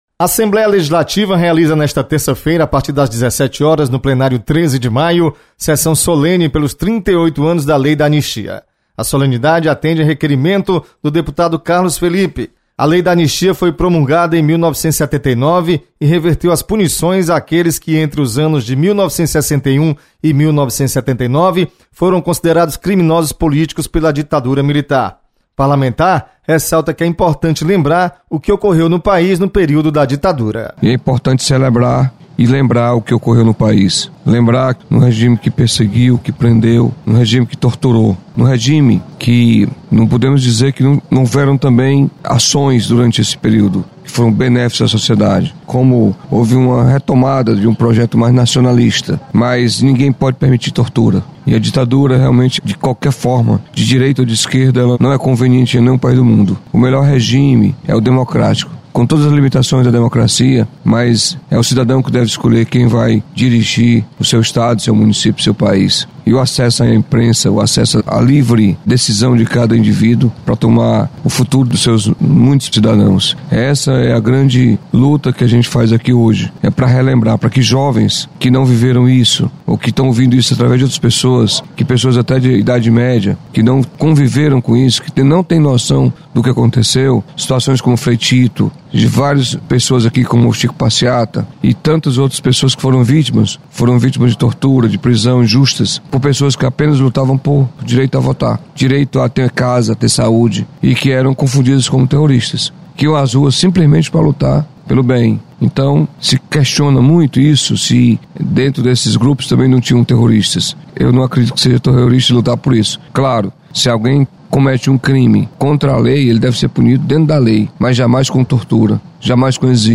Sessão solene lembra 38 anos da Lei da Anistia. Repórter